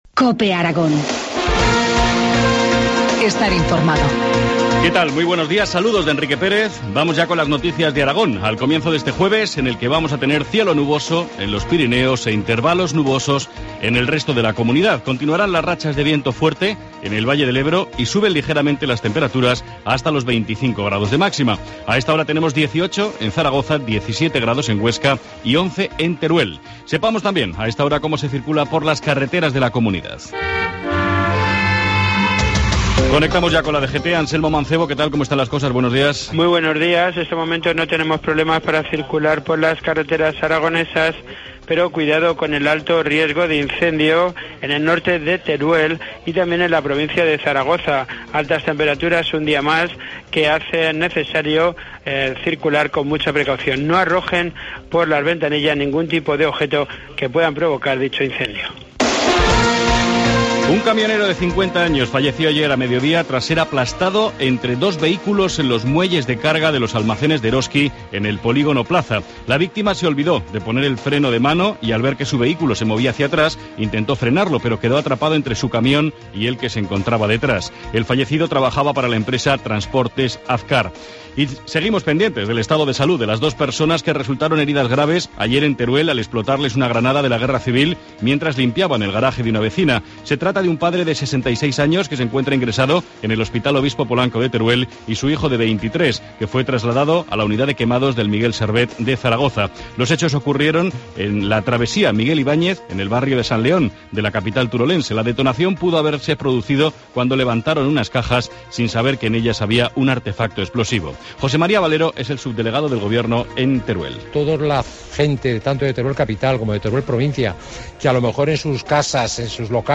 Informativo matinal, jueves 19 de septiembre, 7.25 horas